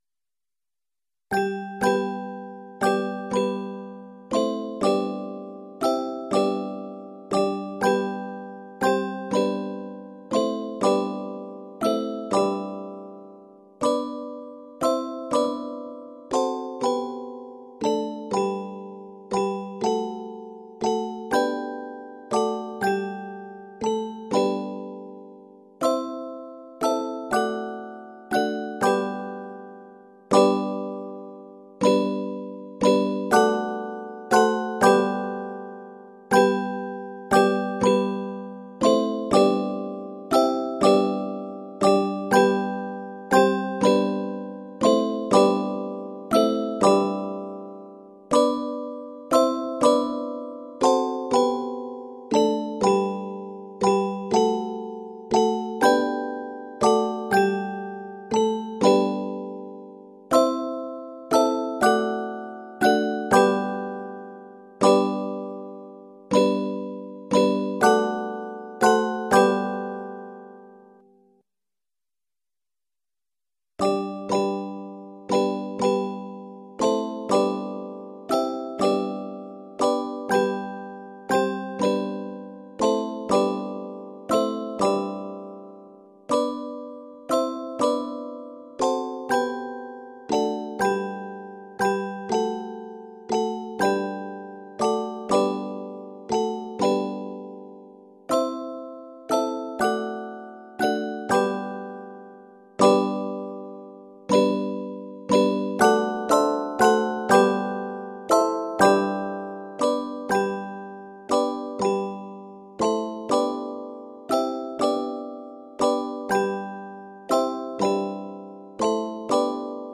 handbells
It is set in G Major.